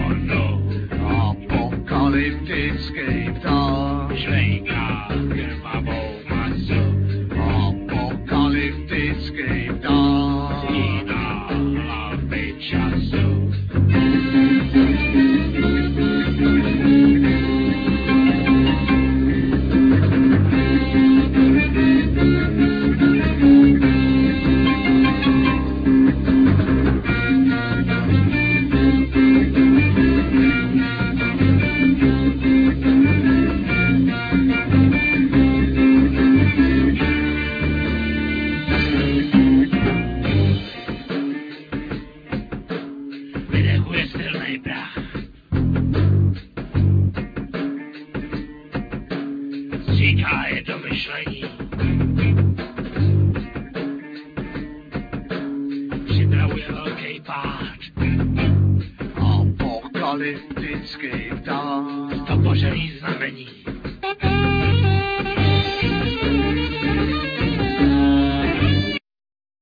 Bass, Vocal
Klaviphone, Vibraphone, Guitar, Vocal
Violin, Vocal
Altsax
Drums
Flute
Theremin